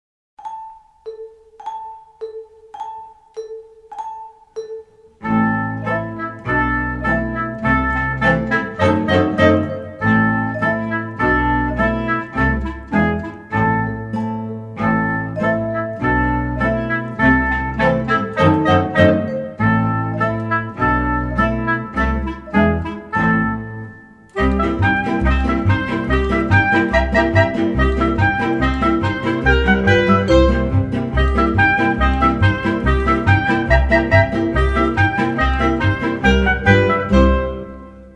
Musik und Anleitungen für Sitztänze